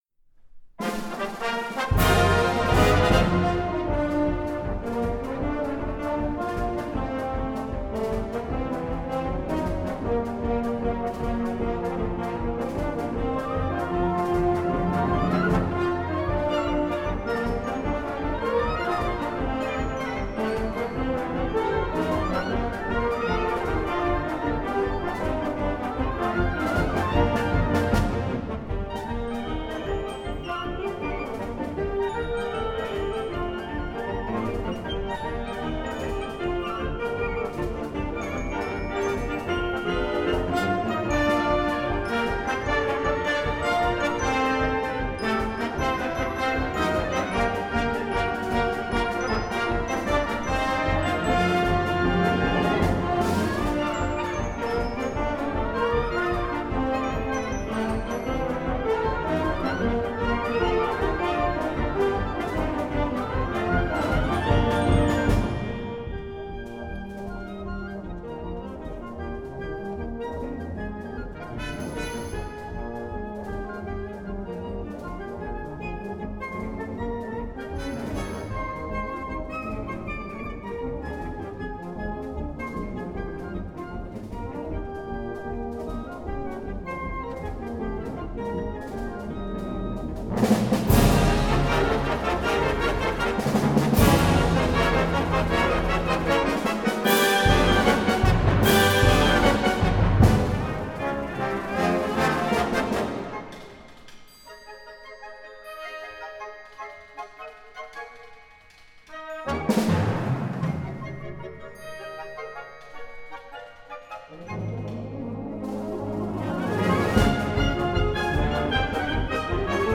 Live performance
concert band